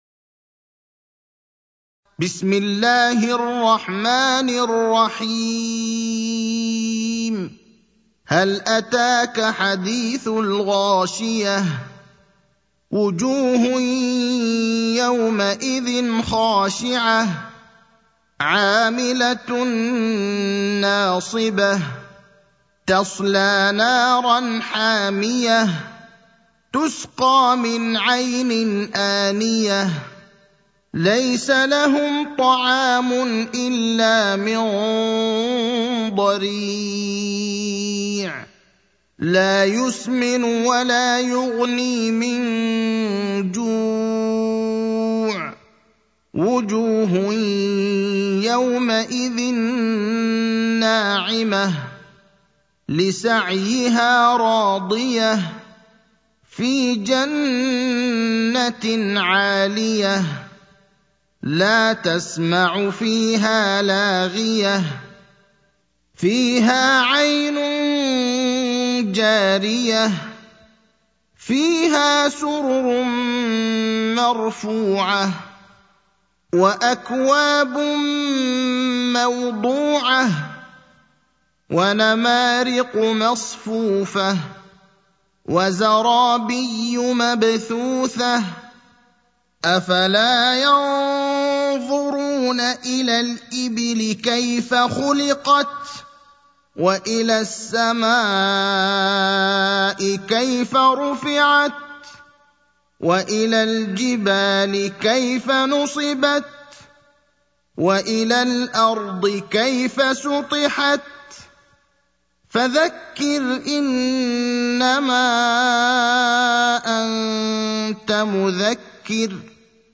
المكان: المسجد النبوي الشيخ: فضيلة الشيخ إبراهيم الأخضر فضيلة الشيخ إبراهيم الأخضر الغاشية (88)